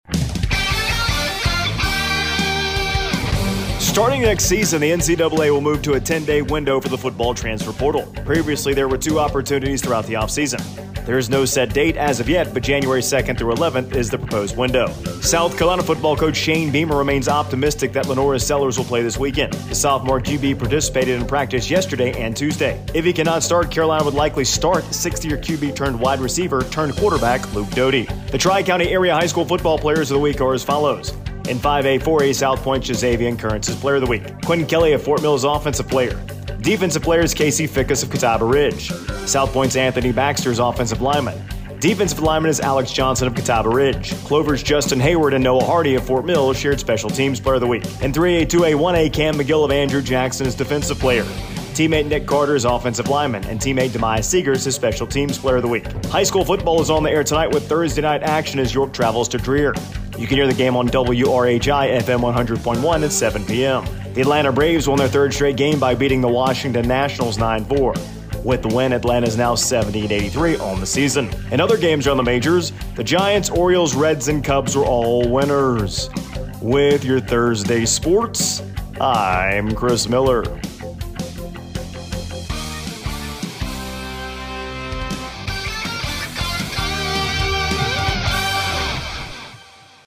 AUDIO: Monday Morning Sports Report